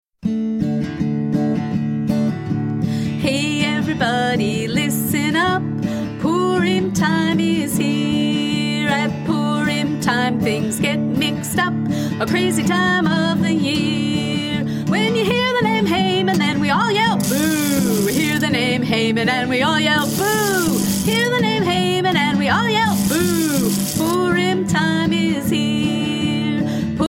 vocals and violin